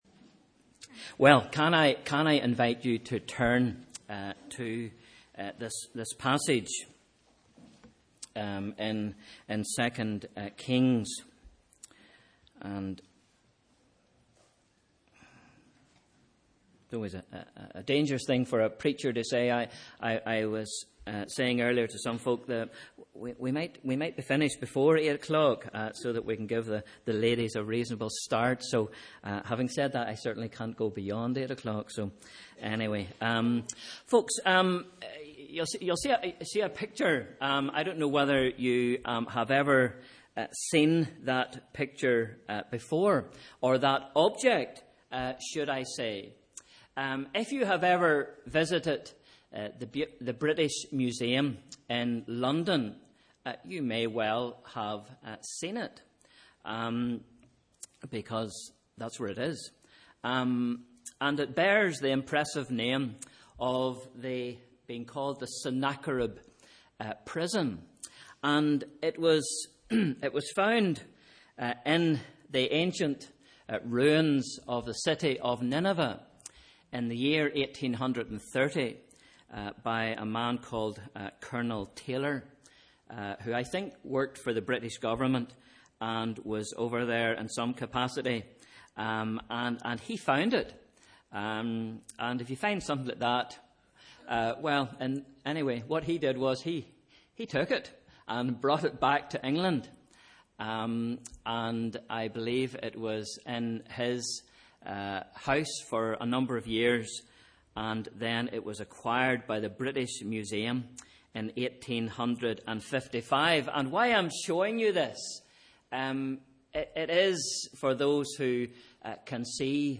Sunday 22nd April 2018 – Evening Service